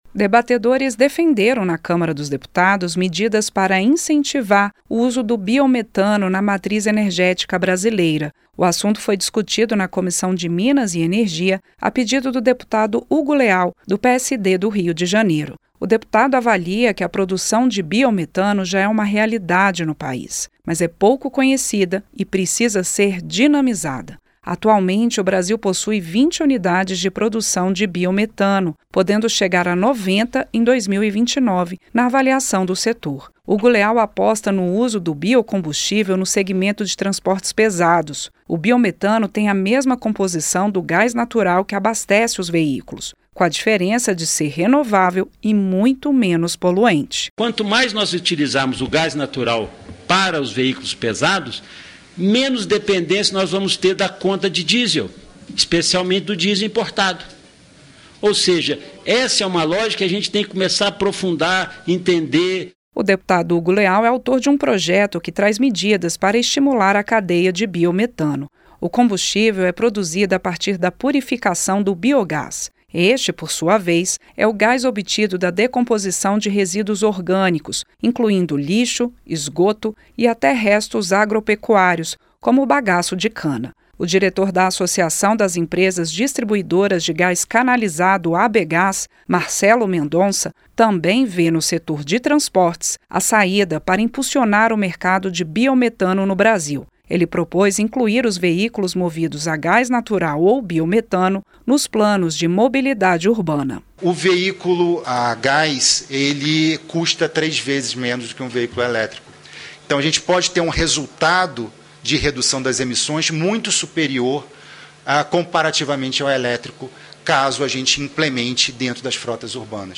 O BIOMETANO PODE SER UMA SAÍDA PARA A REDUÇÃO DA DEPENDÊNCIA DO DIESEL NO PAÍS. FOI O QUE DEFENDERAM REPRESENTANTES DO SETOR OUVIDOS PELA CÂMARA.